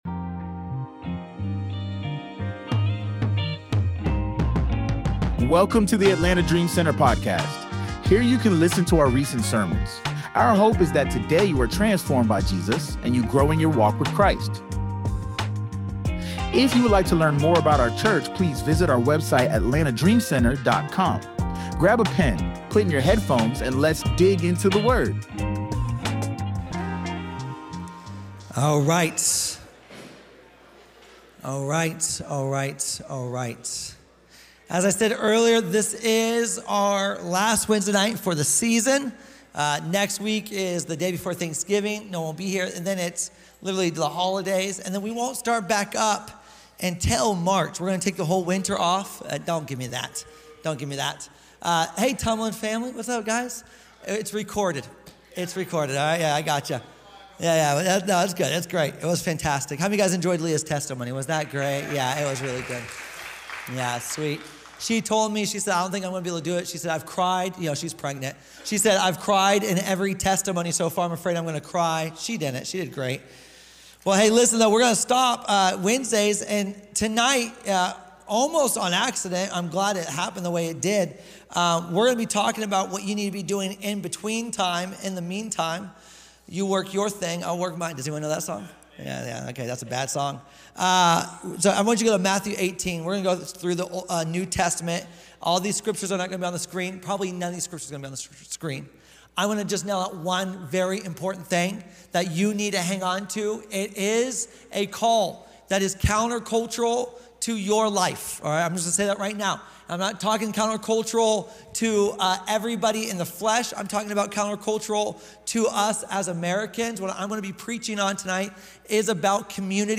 Wednesday Livestream